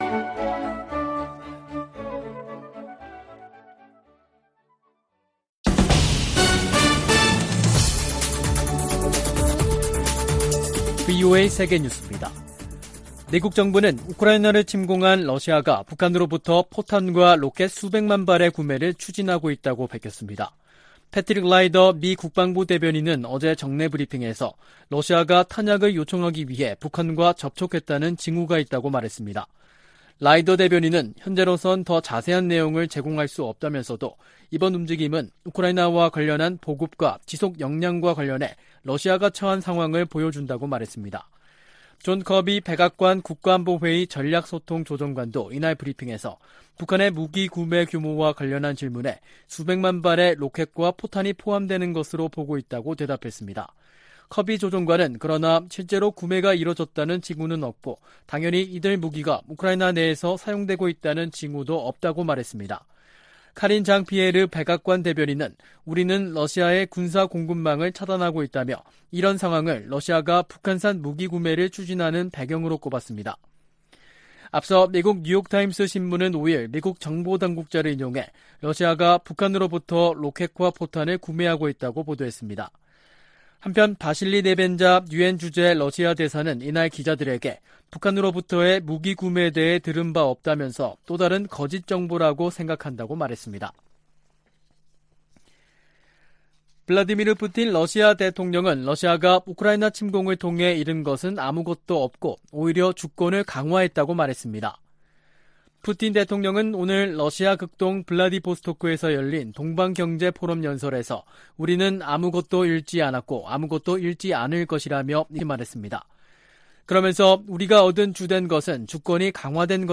VOA 한국어 간판 뉴스 프로그램 '뉴스 투데이', 2022년 9월 7일 3부 방송입니다. 미국과 한국, 일본 북핵 수석대표들이 북한의 도발에 단호히 대응하겠다고 거듭 강조했습니다. 미국 정부가 러시아의 북한 로켓과 포탄 구매에 대해 유엔 안보리 결의 위반이라고 지적했습니다. 유엔이 강제실종과 관련해 북한에 총 362건의 통보문을 보냈지만 단 한 건도 응답하지 않았다며 유감을 나타냈습니다.